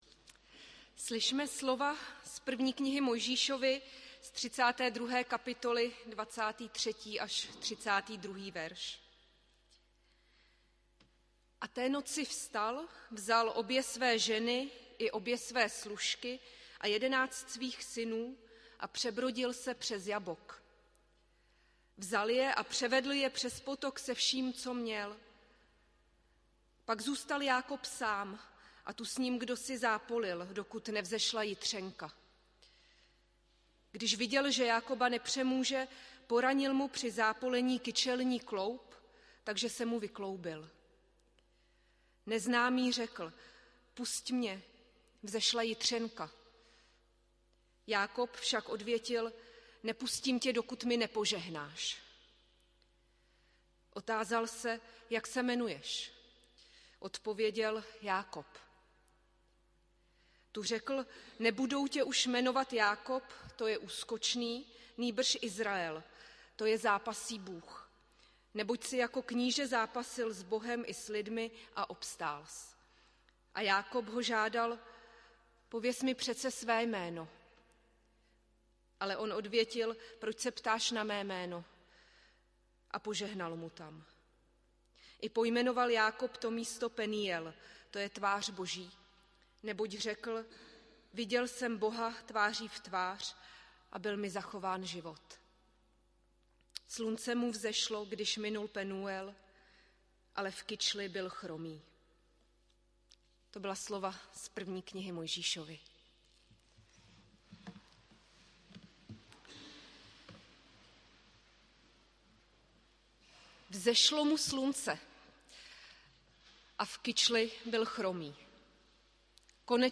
audio kázání